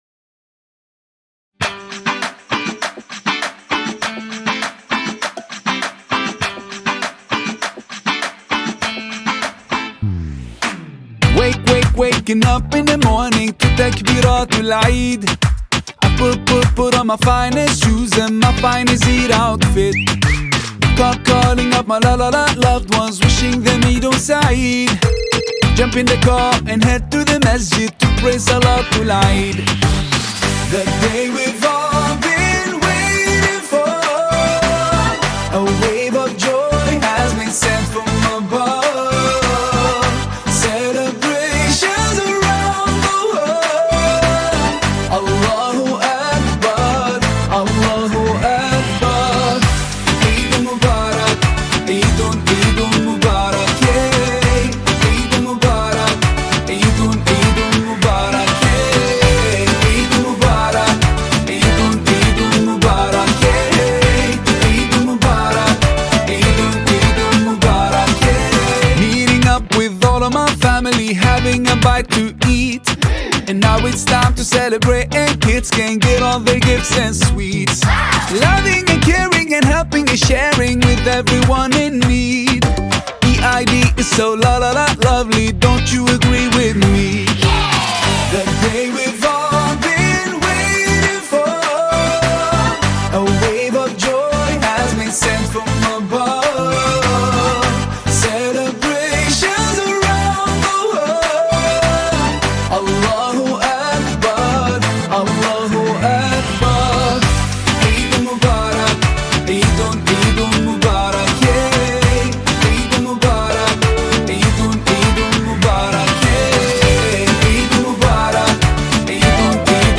Qaswida You may also like